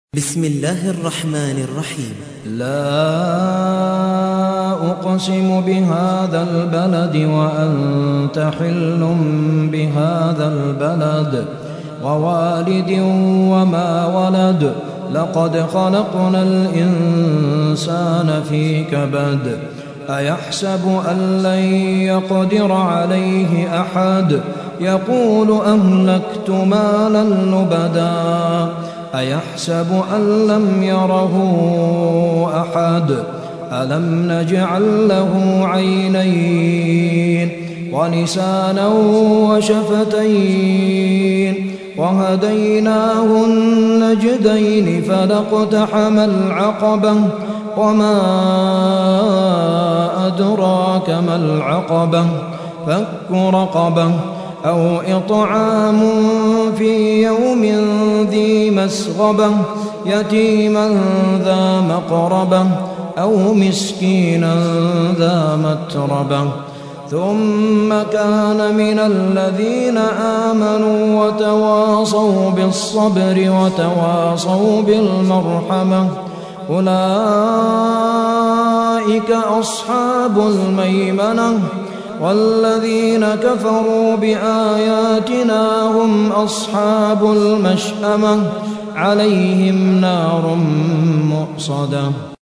90. سورة البلد / القارئ